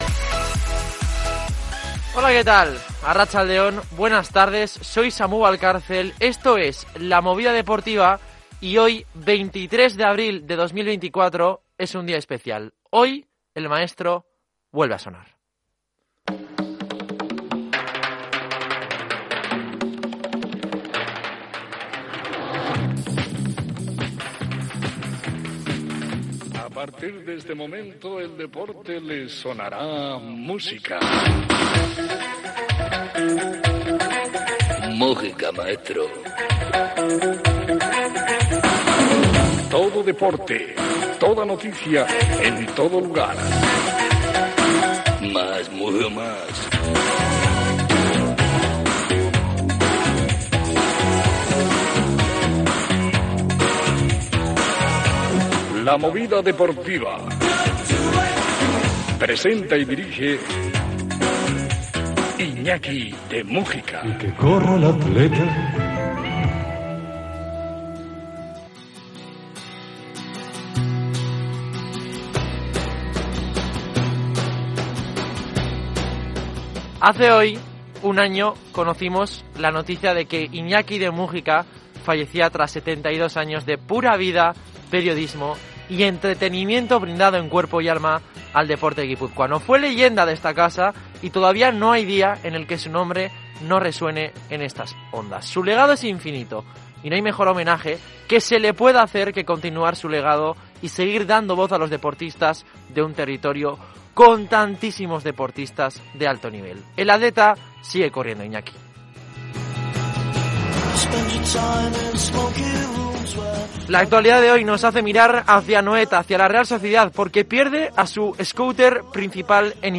Programa deportivo